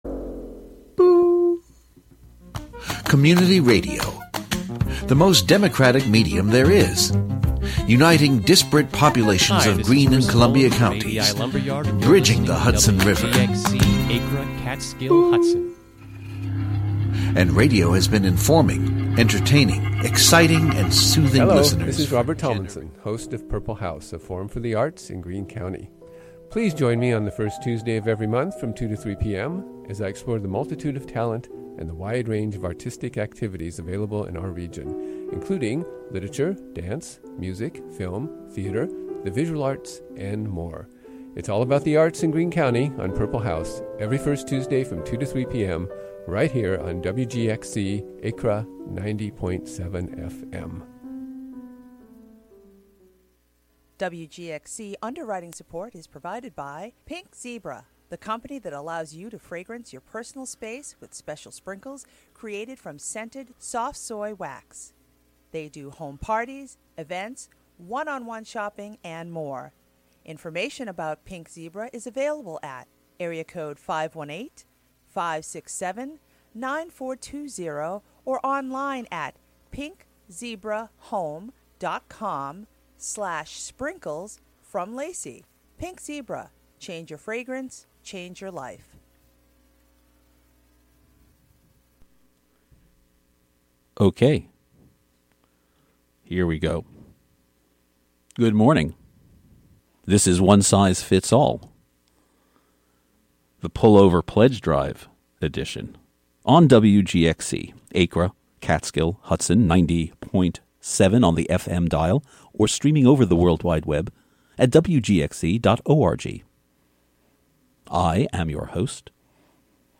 An hour-long investigation into the idea of amplified sound. Broadcast live from the Hudson studio.